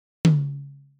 Как убрать с тома (не важно с чего) этот противный хвост затухания?